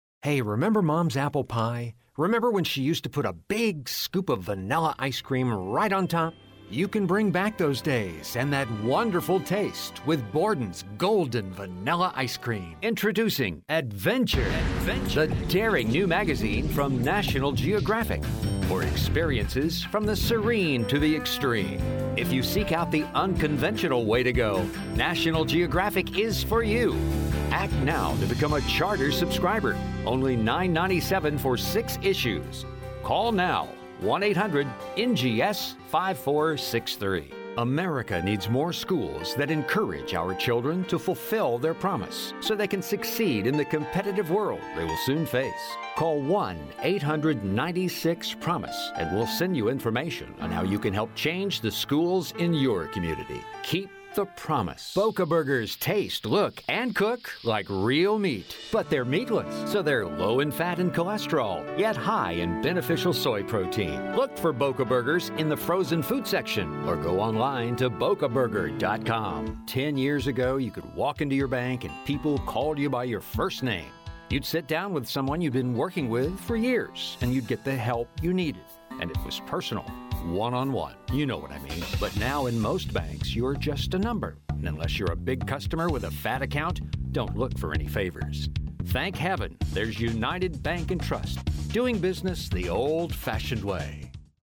He has a very good range, from low to high.
englisch (us)
Sprechprobe: Werbung (Muttersprache):